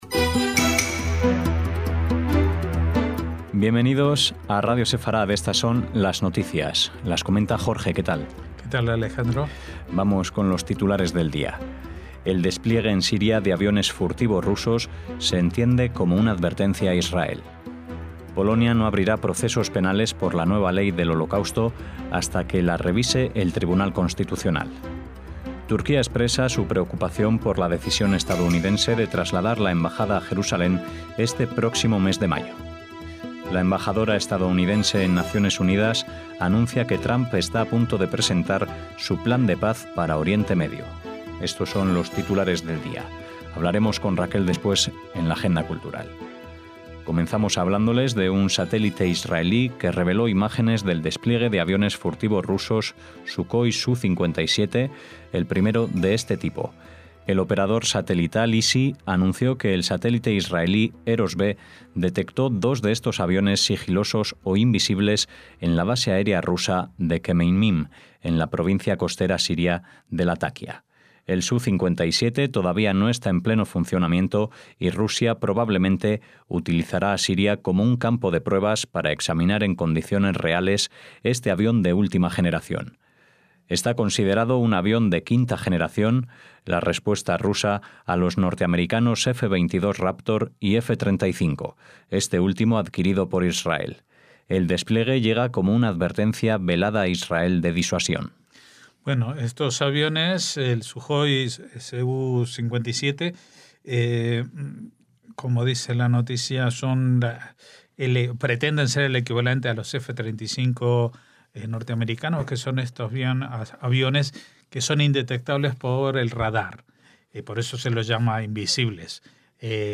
NOTICIAS - Titulares de hoy: el despliegue en Siria de aviones furtivos rusos (en la imagen) se entiende como una advertencia a Israel. Polonia no abrirá procesos penales por la nueva ley del Holocausto hasta que la revise el tribunal constitucional.